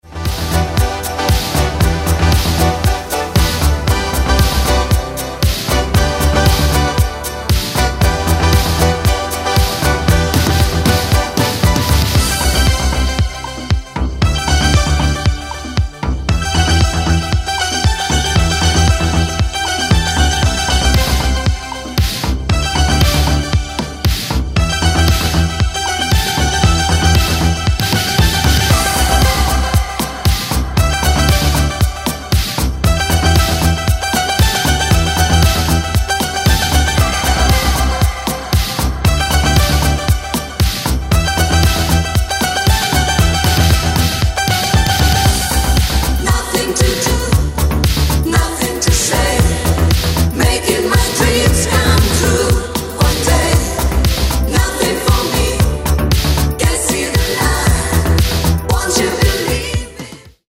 italo
Disco Italo